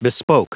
Prononciation du mot bespoke en anglais (fichier audio)
Prononciation du mot : bespoke